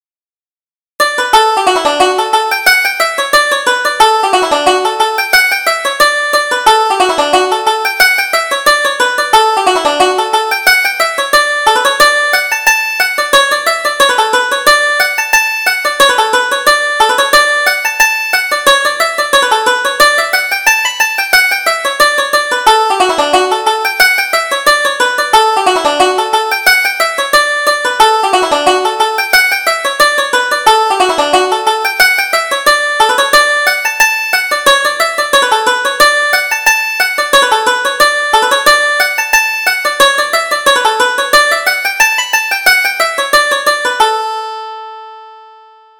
Reel: The New Mail Coach